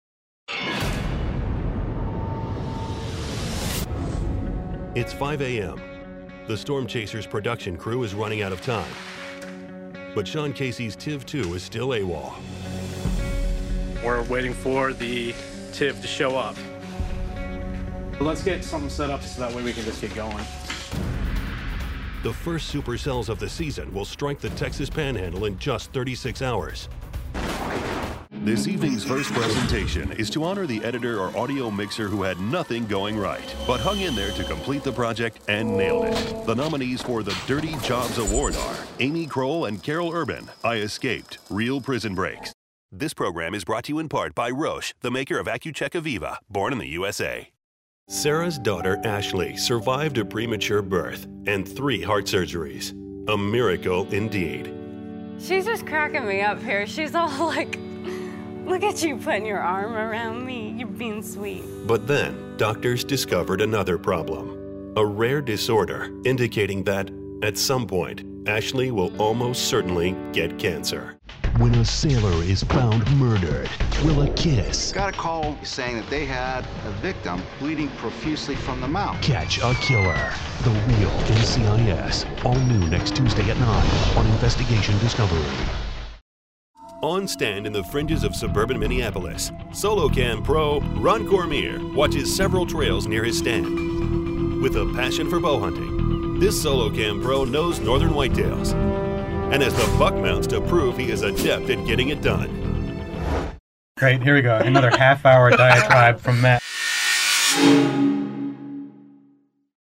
mid-atlantic
middle west
britisch
Sprechprobe: Sonstiges (Muttersprache):